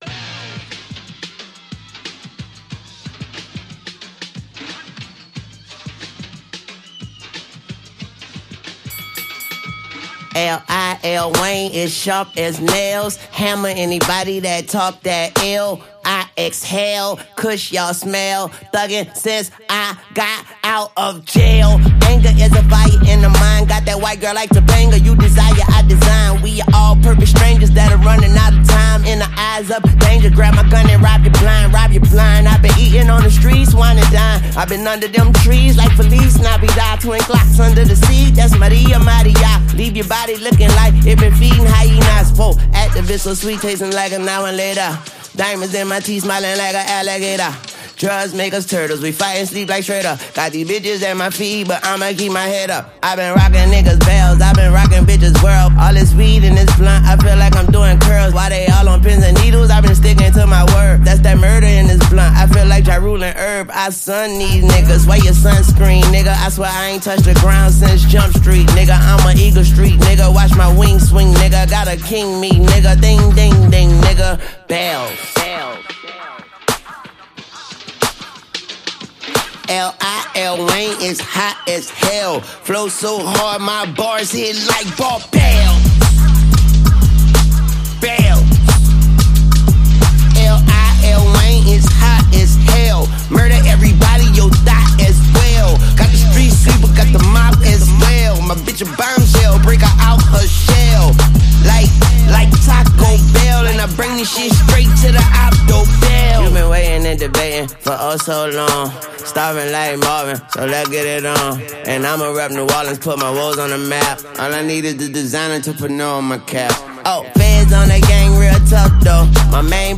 smooth beats
His flow is still creative and full of energy.